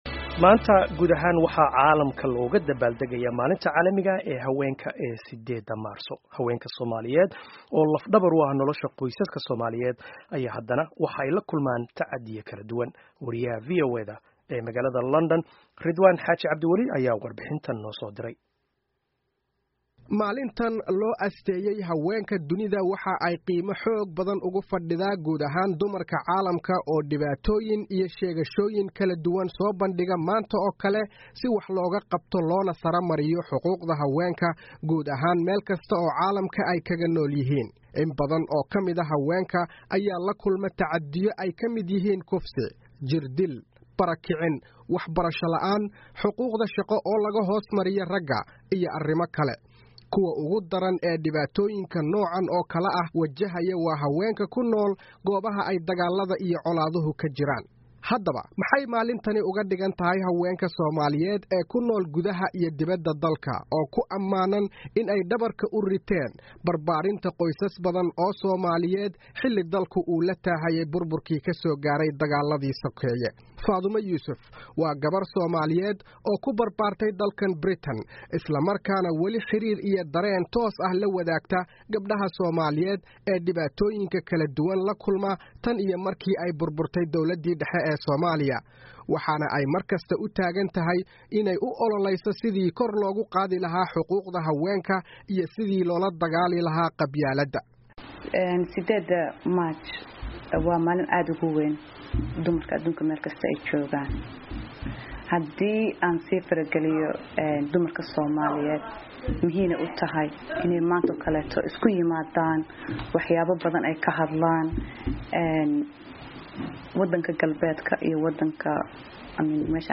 Warbixin: Maalinta Haweenka